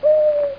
Amiga 8-bit Sampled Voice
owl.mp3